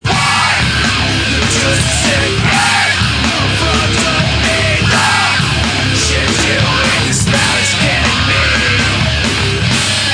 Wah (80 kb, 9 sec)